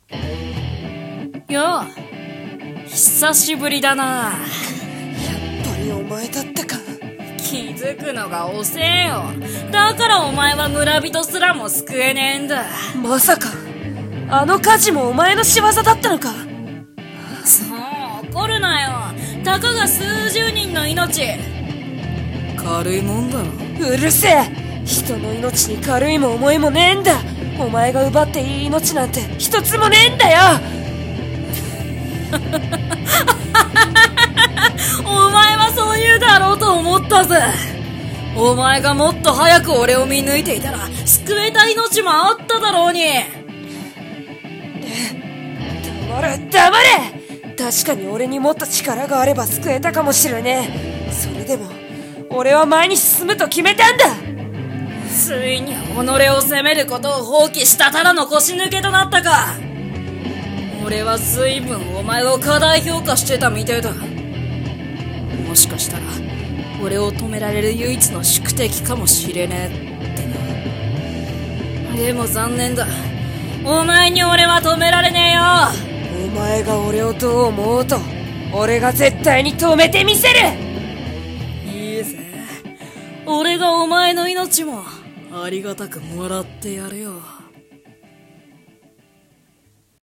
【声劇】最終決戦【台本】